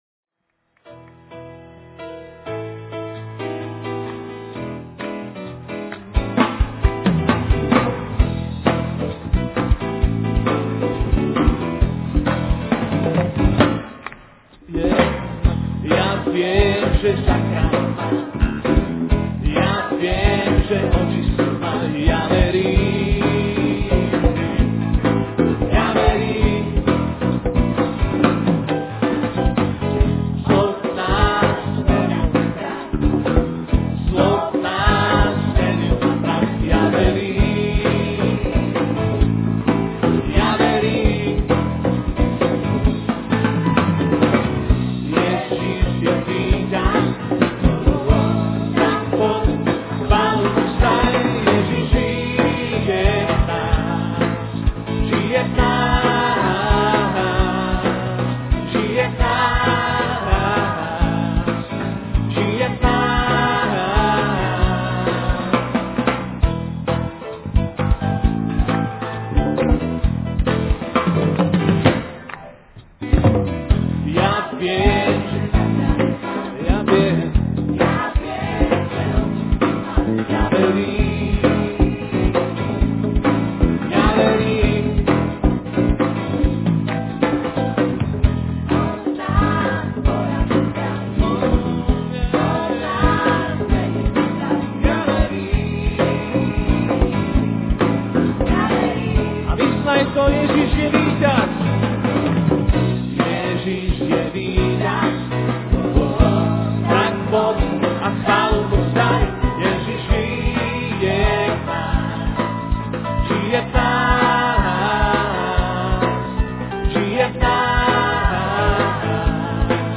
In this sermon, the speaker discusses the importance of different aspects of Christian ministry.